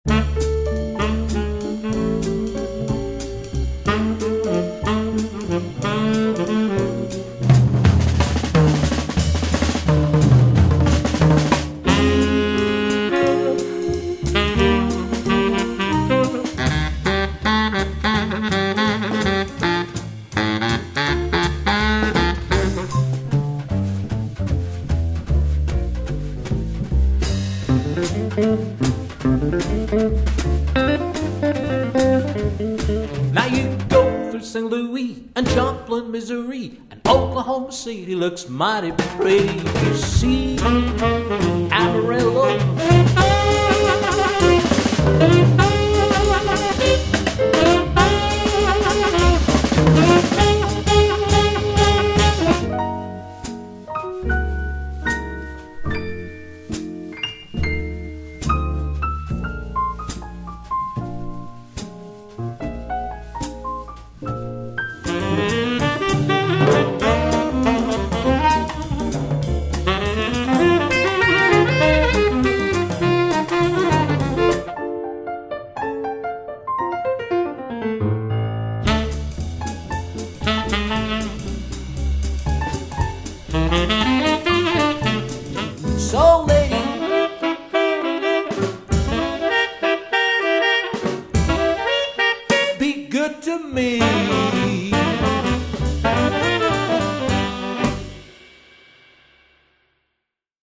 sextette de style Jazz Swing